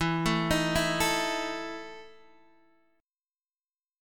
EM7sus4 chord